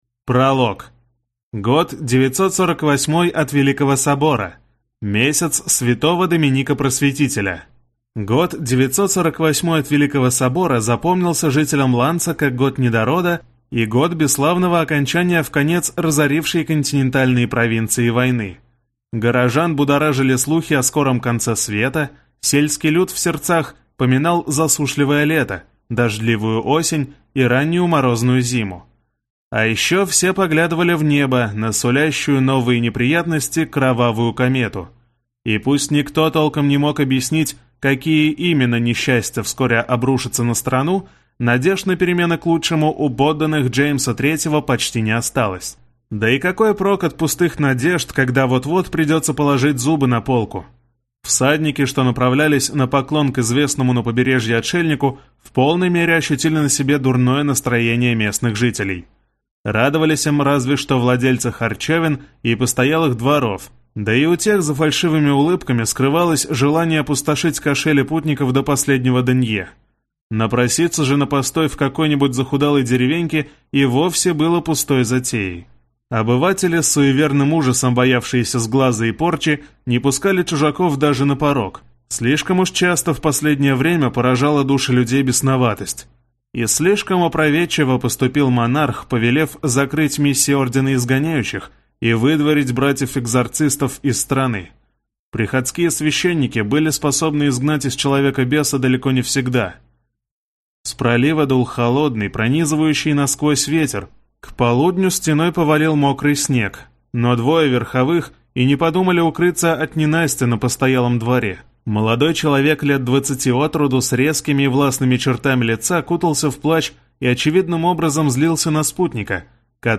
Аудиокнига Экзорцист. Шаг второй. Игра начинается | Библиотека аудиокниг